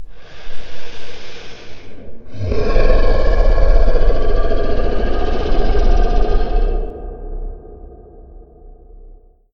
growl-6.ogg